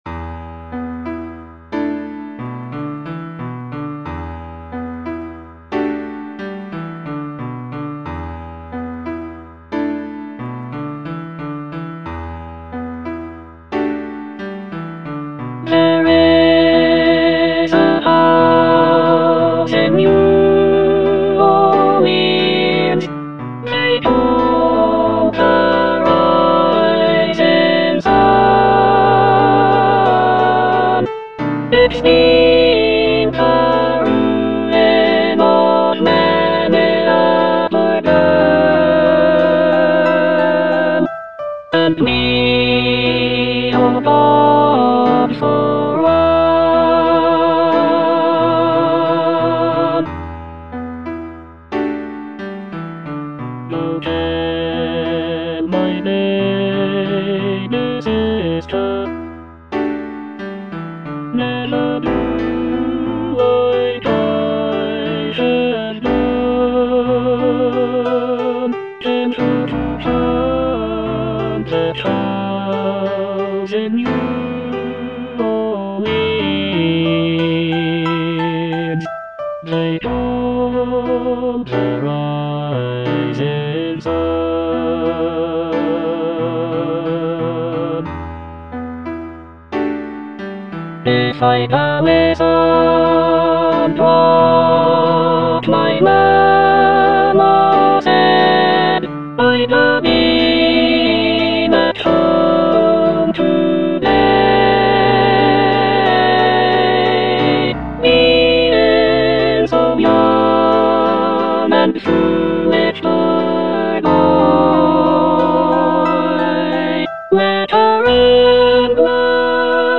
(alto I) (Emphasised voice and other voices) Ads stop